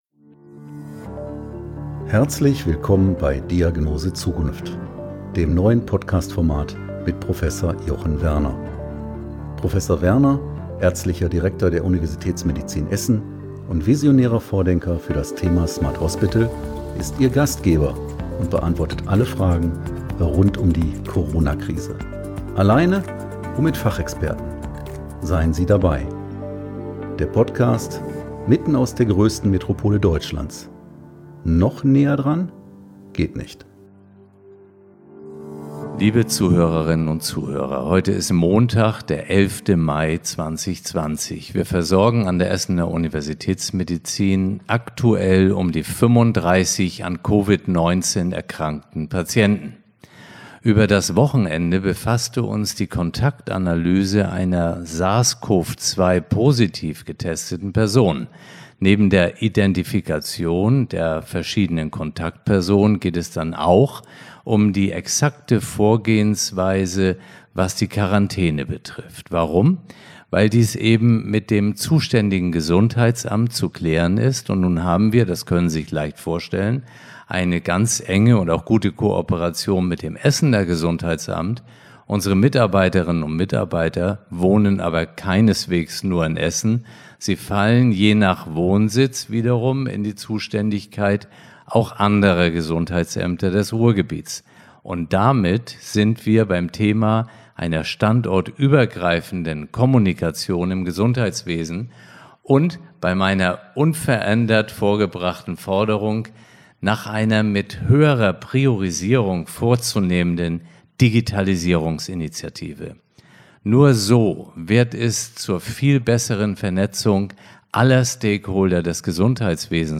Heute bin ich im Gespräch mit Christian Lindner – dem Bundesvorsitzenden der FDP. Dieses Interview möchte Ihnen, liebe Zuhörerinnen und Zuhörer, eine Einschätzung der aktuellen Lage und der damit verbundenen möglichen wirtschaftspolitischen Auswirkungen auf Deutschland geben. Wir sprechen über Vorbilder der öffentlichen Verwaltung, über die Auswirkungen von Corona auf Gastronomie & Hotellerie, über Datenmanagement in der zunehmenden Digitalisierung Deutschlands und über vieles mehr.